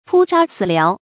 撲殺此獠 注音： ㄆㄨ ㄕㄚ ㄘㄧˇ ㄌㄠˇ 讀音讀法： 意思解釋： 撲殺：打死；獠：古時罵人的話。